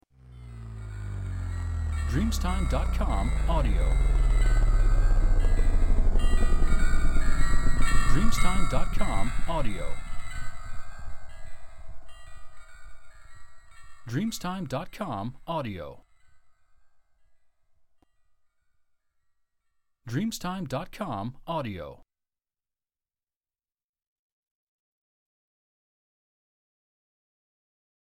Transizione di goccia del carillon di vento